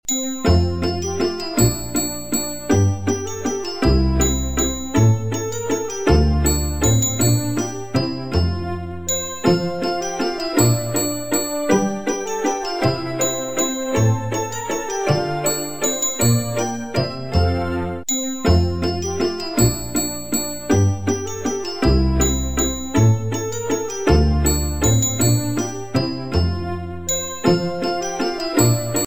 Kategorie Świąteczne